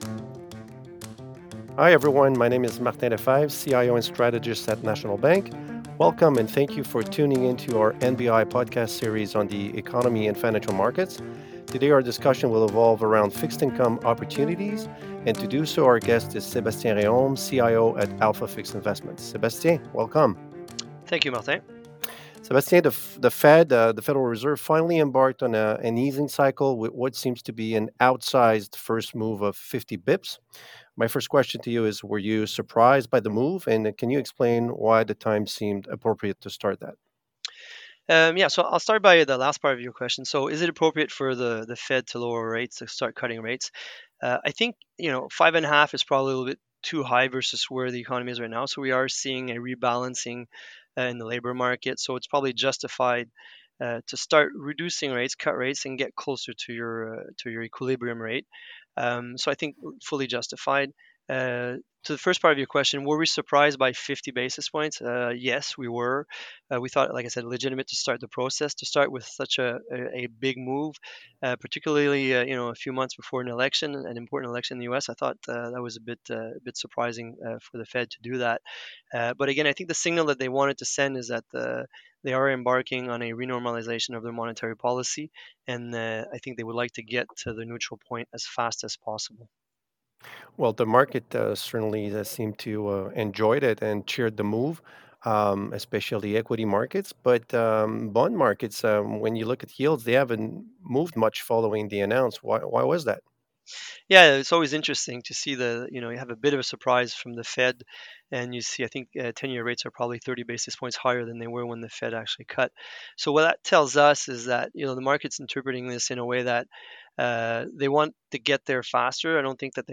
host
guest